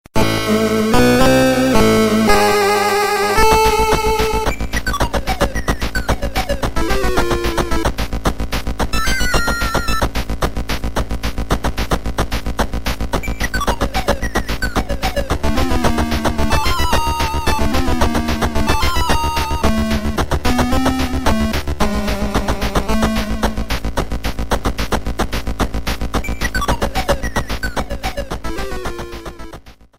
Final boss theme